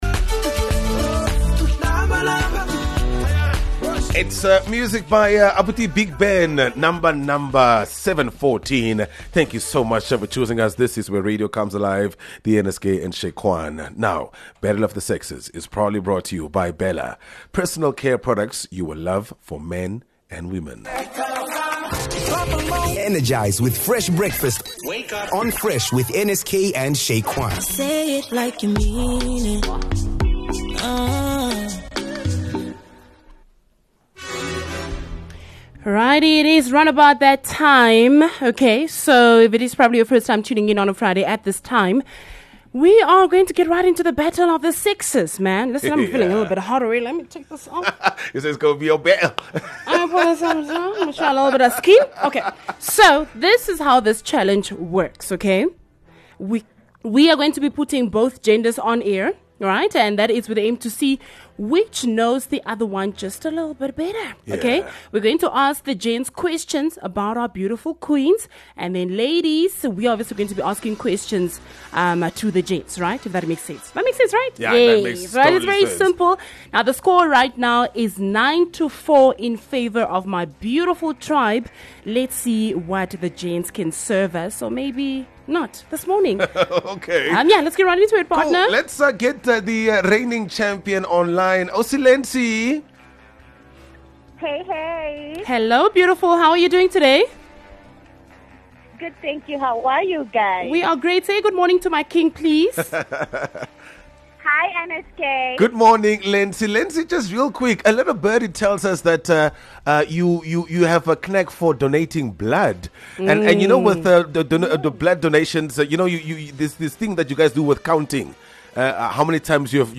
Battle of the Sexes is probably the most dramatic game show on Namibian radio. This is the bit where we have both genders on air with the aim to see which knows more about the other. So we will ask the gents questions about the ladies and ladies...we will ask questions about the gents!